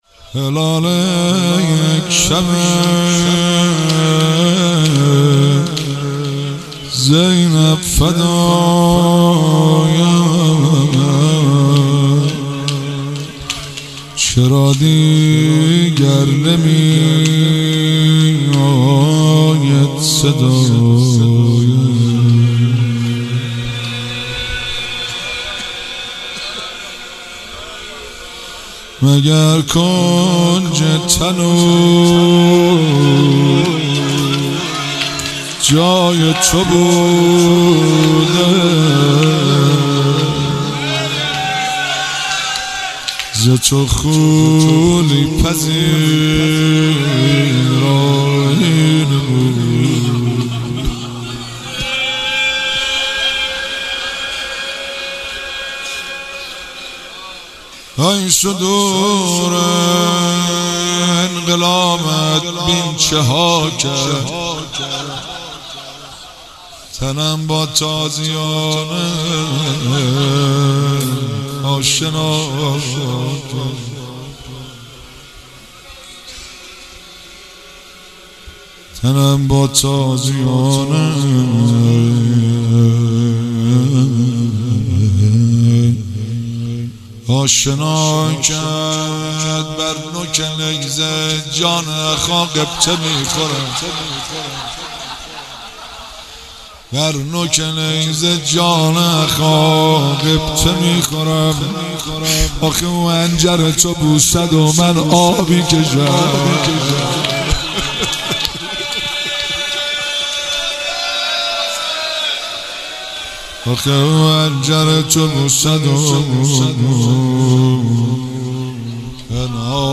جلسه هفتگی هیئت فاطمیون قم (۲۴بهمن۹۷)